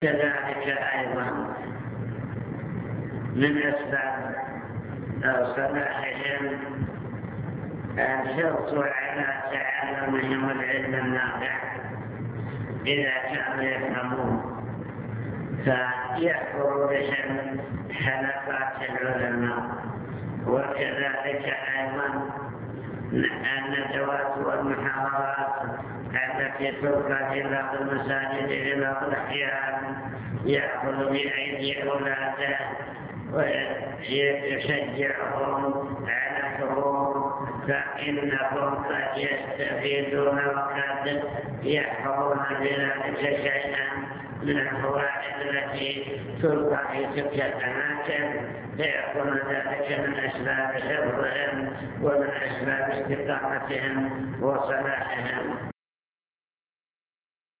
المكتبة الصوتية  تسجيلات - محاضرات ودروس  محاضرة بعنوان توجيهات للأسرة المسلمة طرق وأسباب صلاح الأبناء